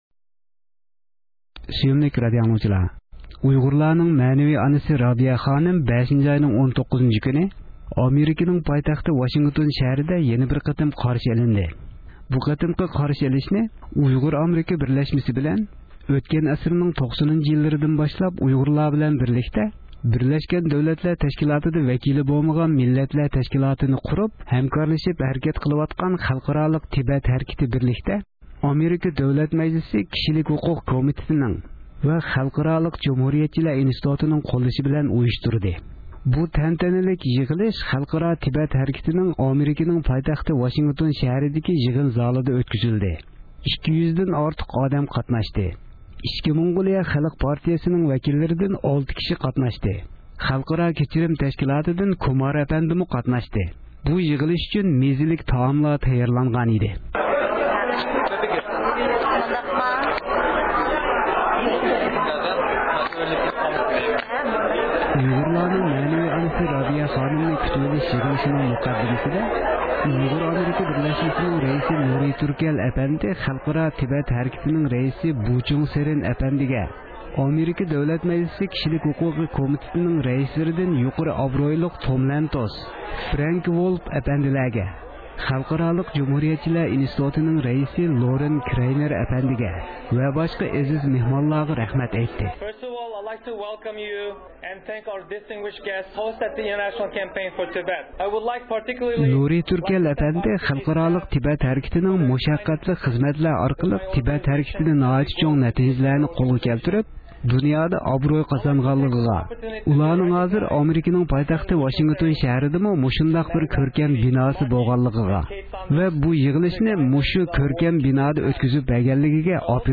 بۇ تەنتەنىلىك يىغىلىش خەلقئارالىق تىبەت ھەرىكىتىنىڭ ئامېرىكىنىڭ پايتەختى ۋاشىنگتون شەھىرىدىكى يىغىن زالىدا ئۆتكۈزۈلدى. 200 دىن ئارتۇق ئادەم قاتناشتى.
ئۇيغۇرلارنىڭ مەنىۋى ئانىسى رابىيە خانىممۇ بۇ يىغىلىشتا سۆز قىلدى.